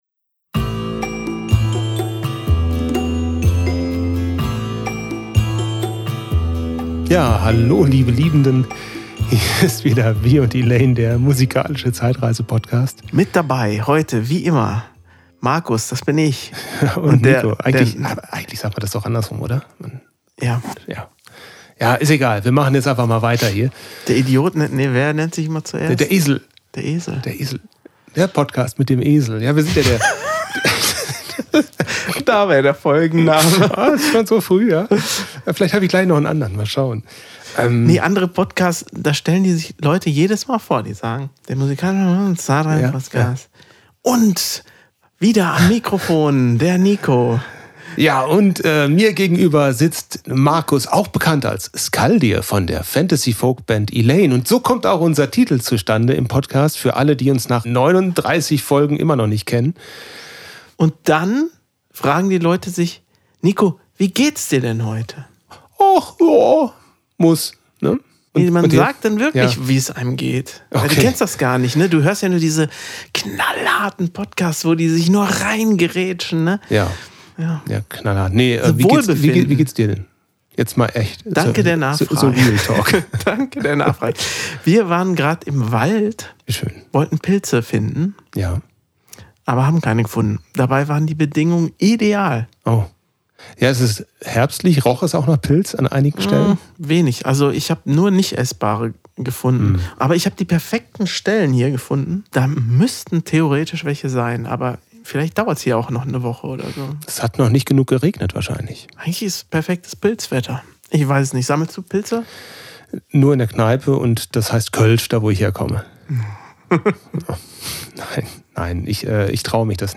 Aber natürlich bleibt genug Zeit für ein buntes Themen-Potpourri rund ums Pilzesammeln im Wald, das Programmieren von Videorekordern, dilettantische Sprechversuche in österreichischer Dialektik und das Geheimnis des Roy Black!